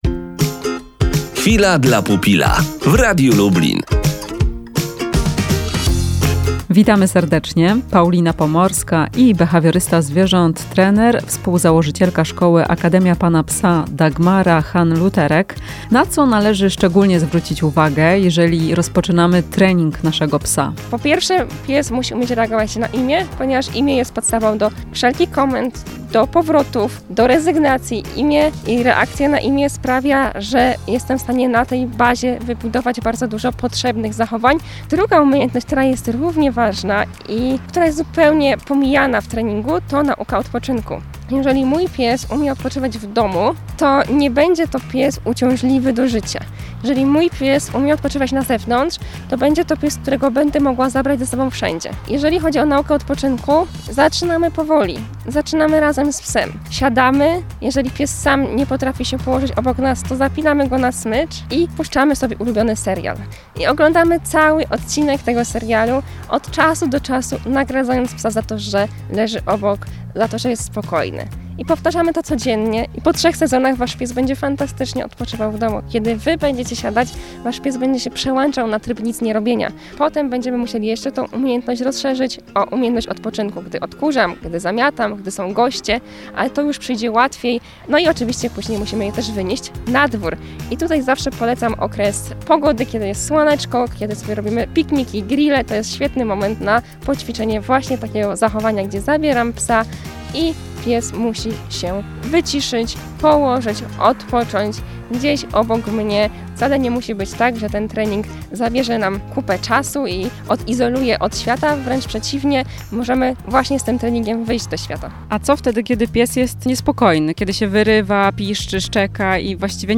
O tym w rozmowie z behawiorystką, trenerem psów